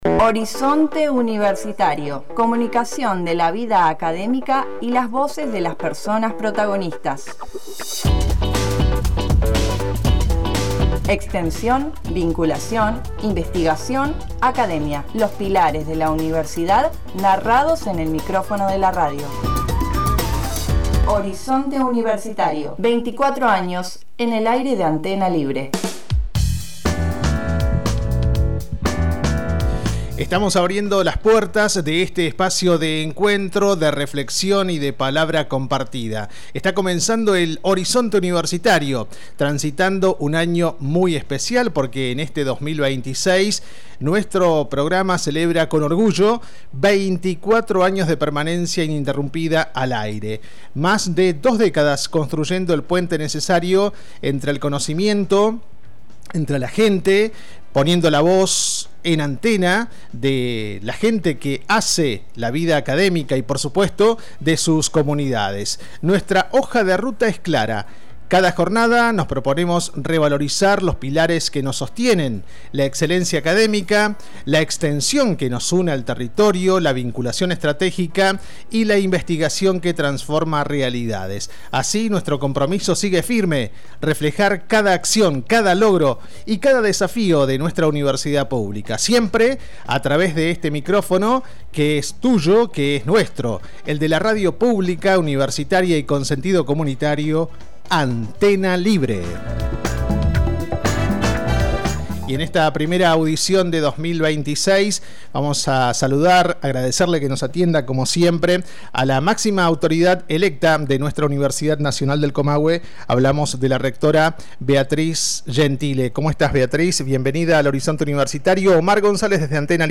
La rectora de la Universidad Nacional del Comahue (UNC), Beatriz Gentile, inauguró el ciclo de entrevistas de Horizonte Universitario, audición que cumple 24 años ininterrumpidos en Antena Libre.